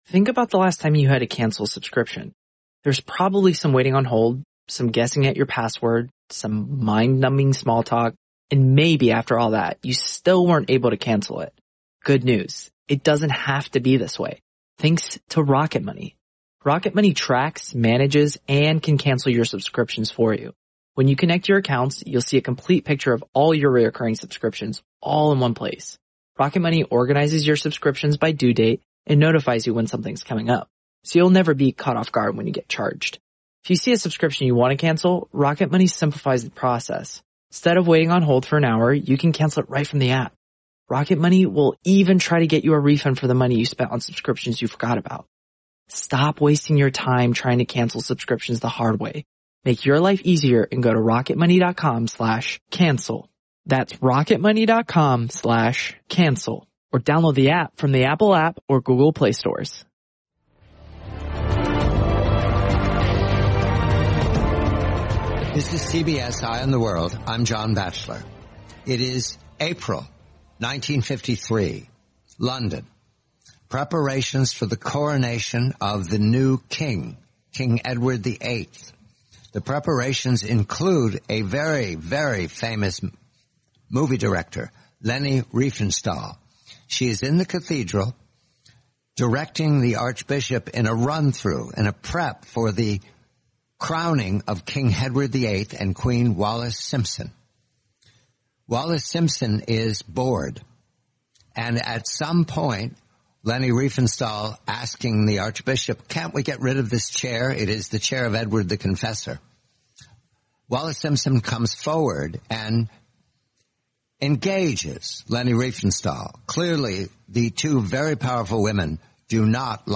The complete, 20-minute interview, June 26, 2021